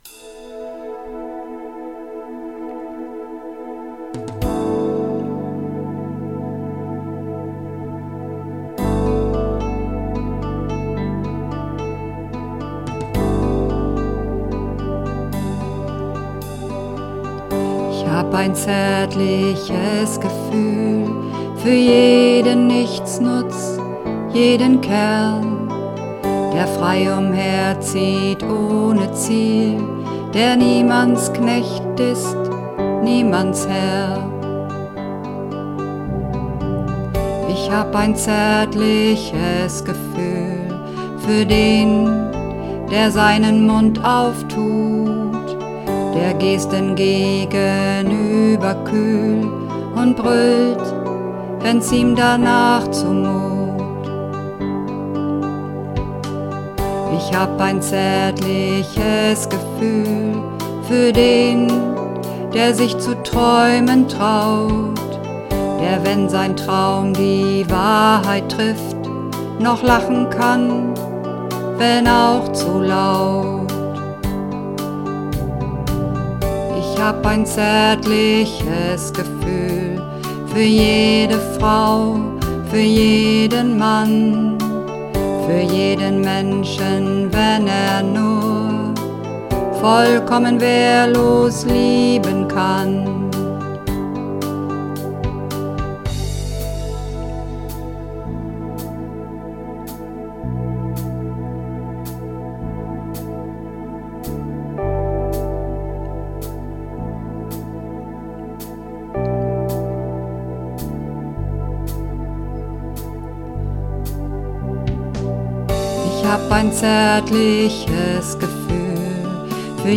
Ich_hab_ein_zaertliches_Gefuehl__2_Bass.mp3